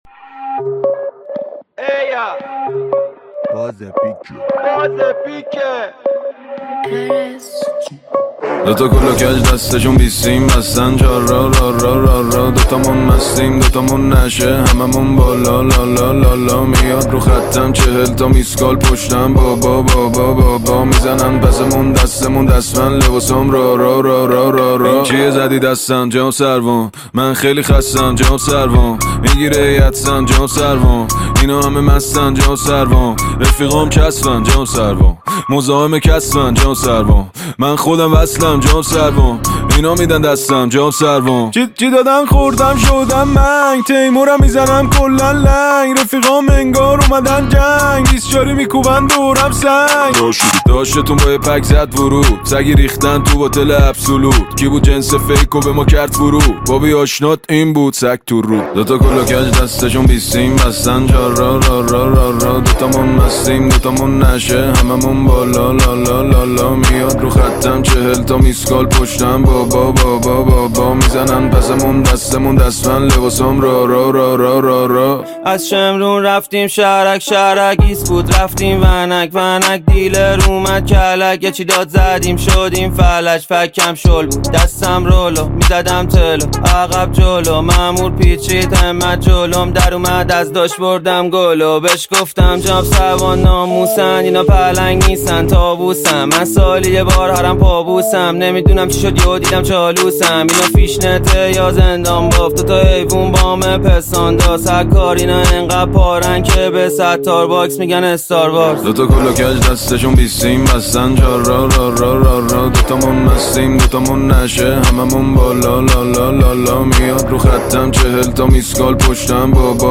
یکی از ترک‌های پرانرژی و خاص رپ فارسی است
اگر به دنبال یک آهنگ فان، خیابونی و پرقدرت در سبک رپ هستید